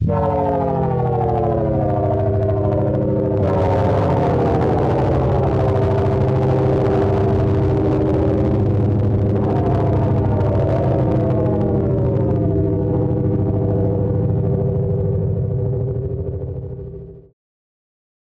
The sounds are mostly space-age, weird naughty noises, and buzzy things -- cutting edge for 1976.